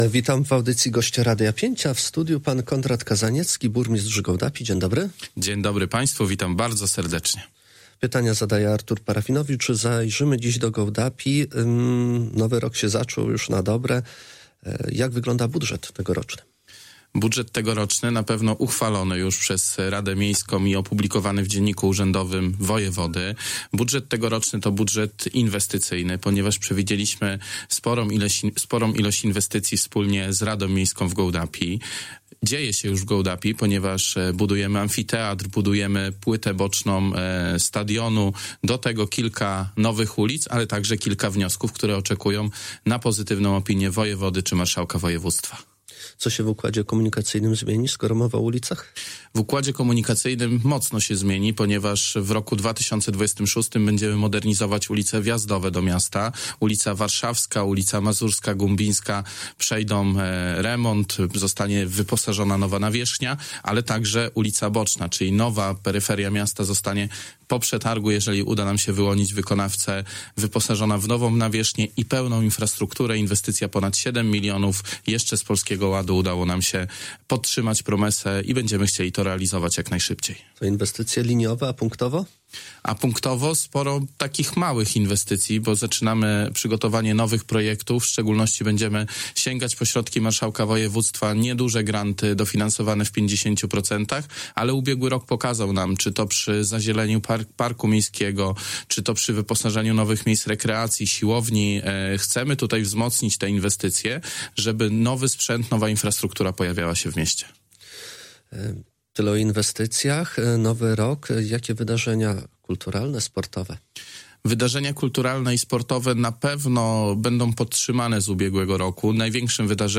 Cała rozmowa na ten temat poniżej: